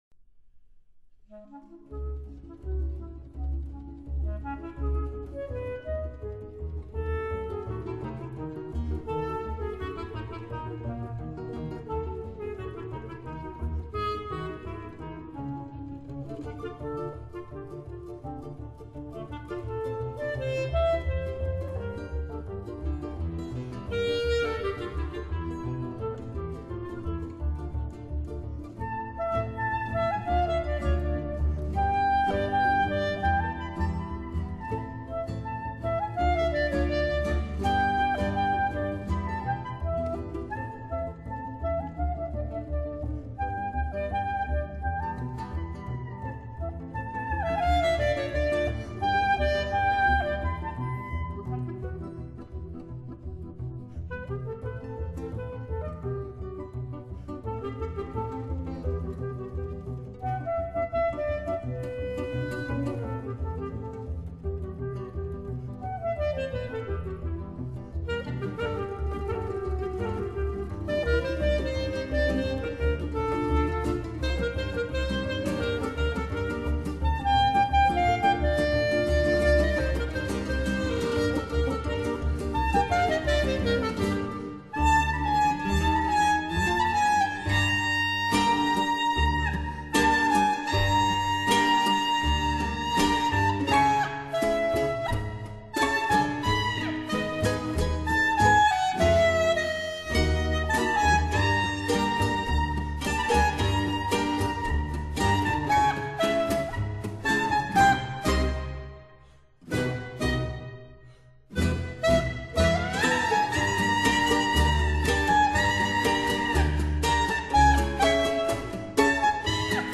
Genre: jazz, blues, instrumental, world folk, clarinet